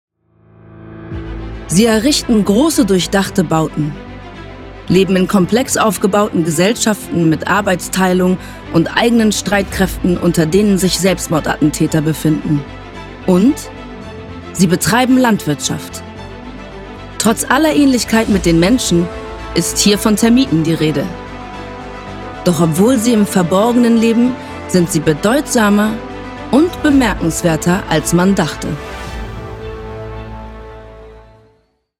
dunkel, sonor, souverän, markant
Mittel minus (25-45)
Norddeutsch
Termiten Doku
Doku